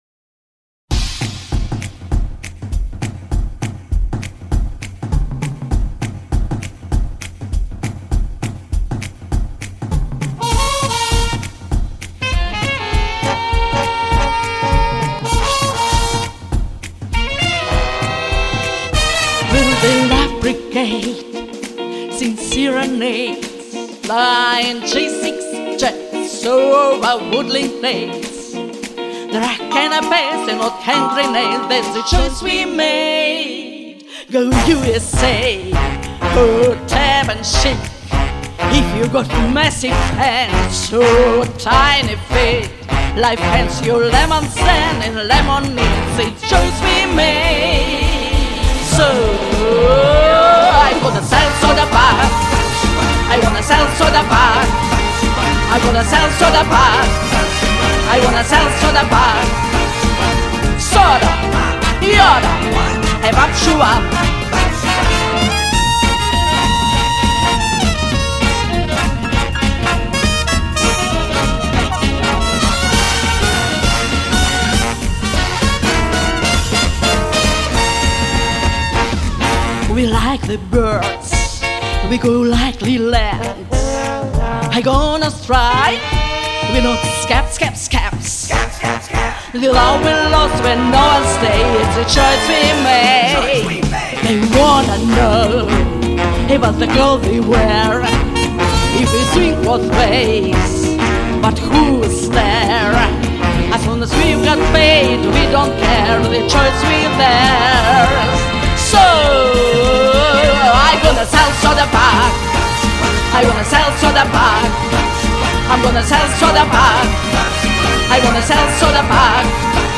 Комментарий инициатора: желательно - jazz-standart...))